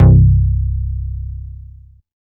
84 SJ BASS.wav